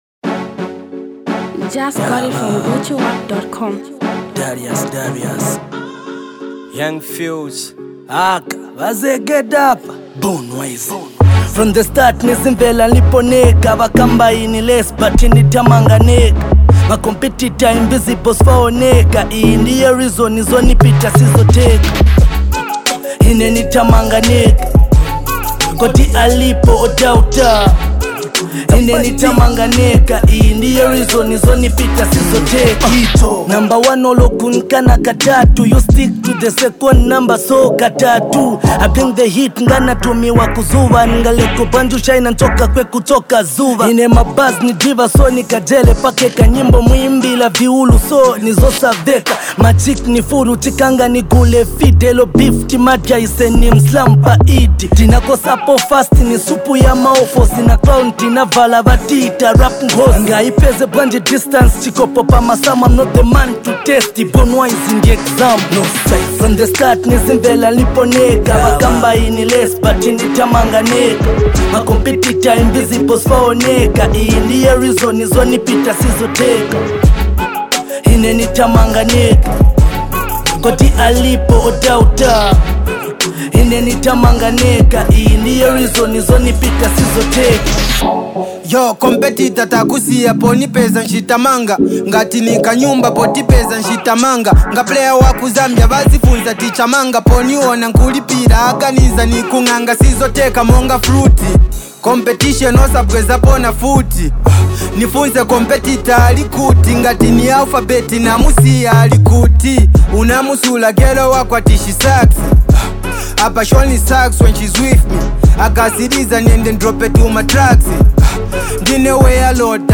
Zambian Mp3 Music
rap
street anthem